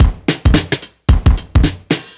OTG_Kit 5_HeavySwing_110-B.wav